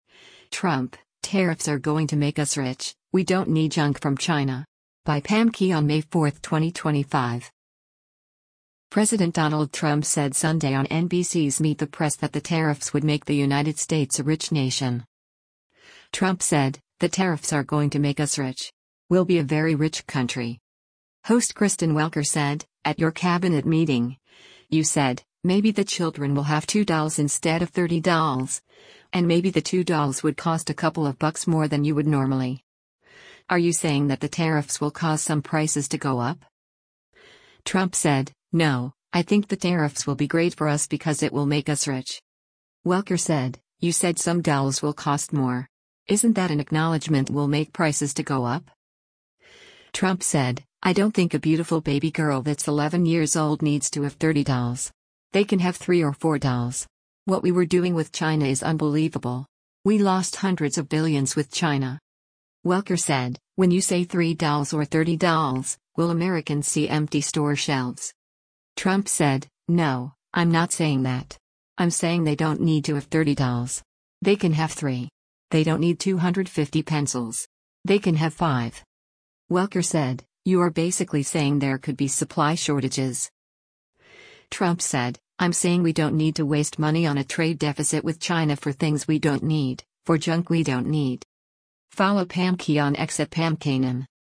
President Donald Trump said Sunday on NBC’s “Meet the Press” that the tariffs would make the United States a rich nation.